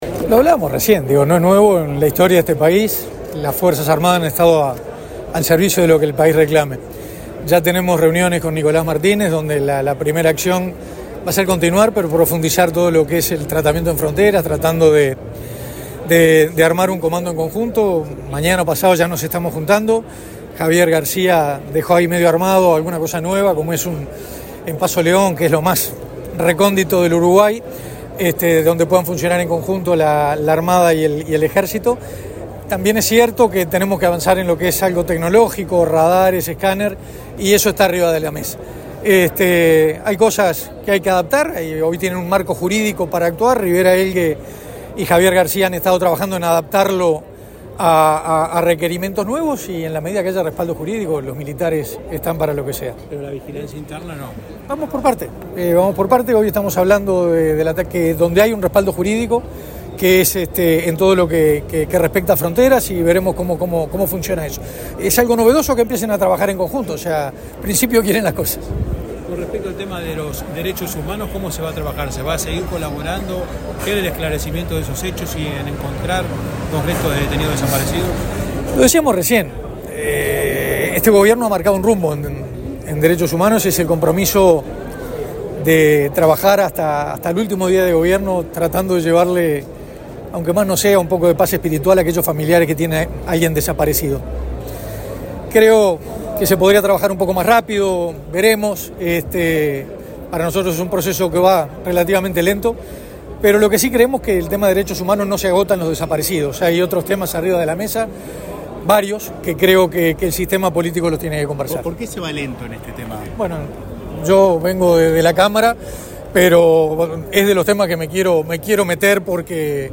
Declaraciones del nuevo ministro de Defensa Nacional, Armando Castaingdebat
Este lunes 4, en el Ministerio de Defensa Nacional, se realizó el acto de asunción del nuevo secretario de Estado, Armando Castaingdebat.